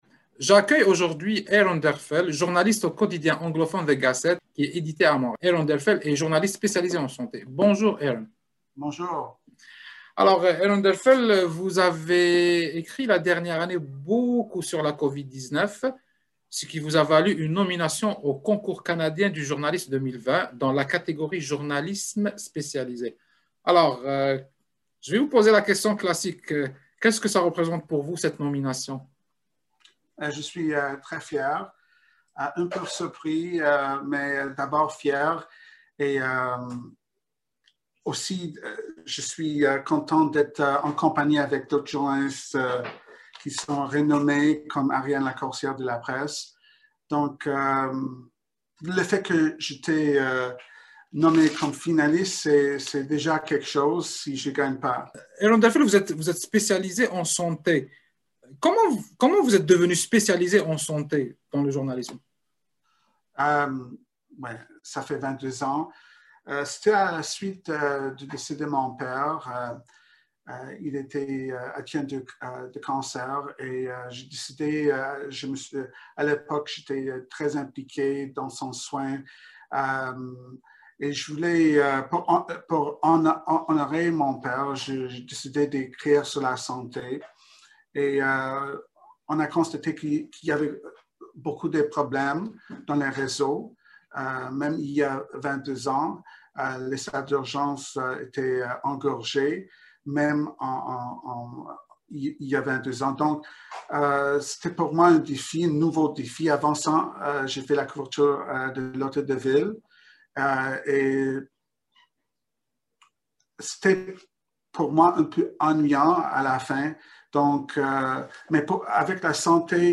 En entrevue avec Radio Canada International